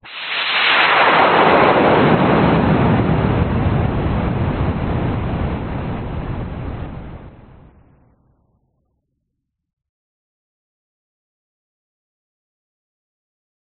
woosh fx 2
Tag: 效果